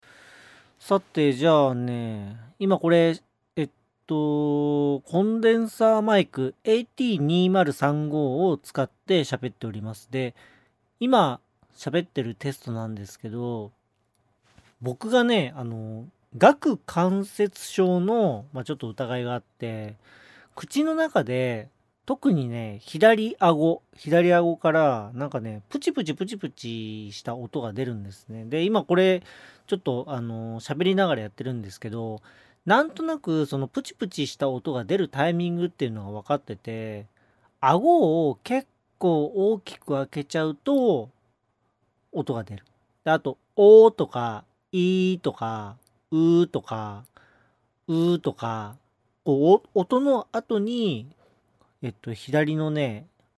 ちょっと時間あるので声とって波形みてるけど今自分の中で気になってる部分が波形のこのあたりのノイズ。ここに顎の音のクリック音が入ってるので結構タチ悪い。
40秒くらいから顕著にでます